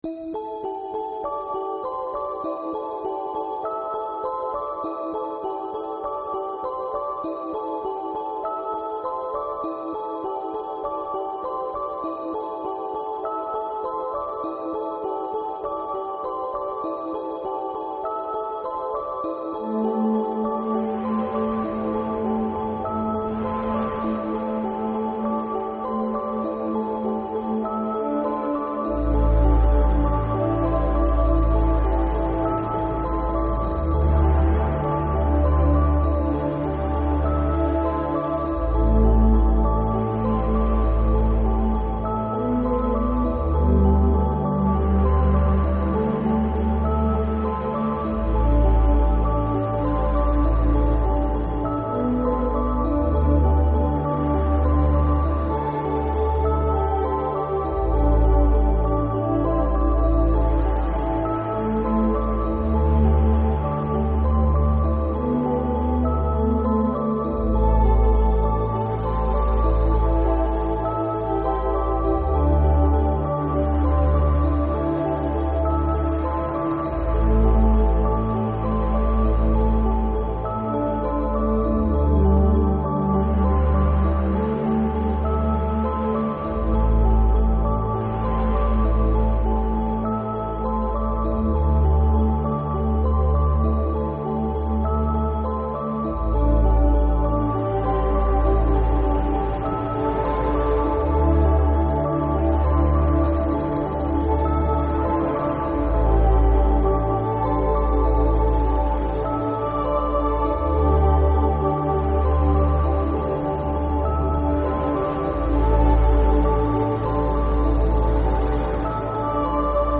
ambient synth from LA composer